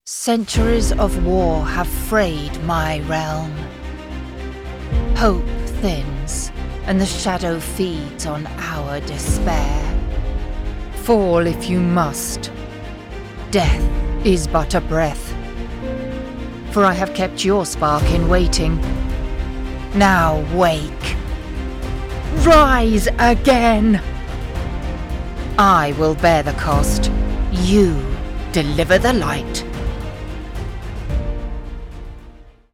Female
I offer an RP British voice with excellent diction and an expressive, nuanced delivery.
My voice is warm, clear, and naturally engaging.
Video Games